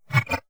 Scifi Screen UI 2.wav